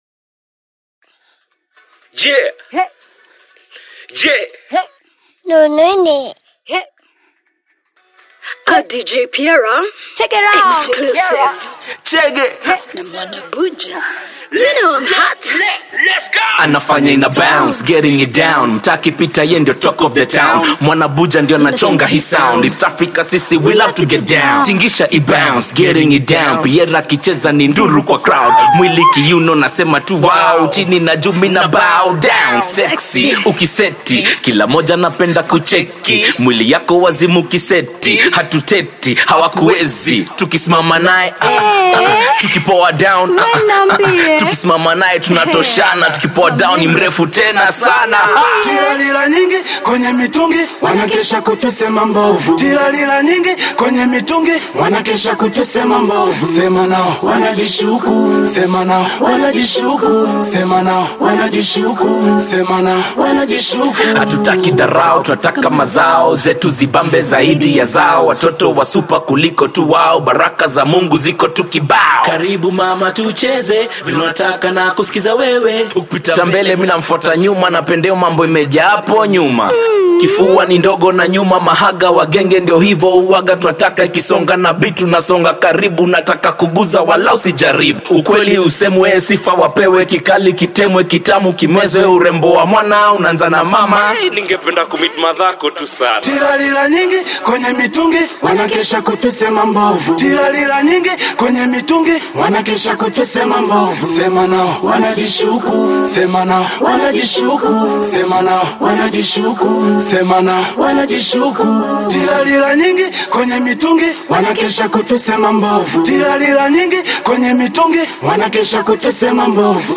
Nice record with some nice punchlines
[Download the VOCALS HERE: RIGHT CLICK AND SAVE AS]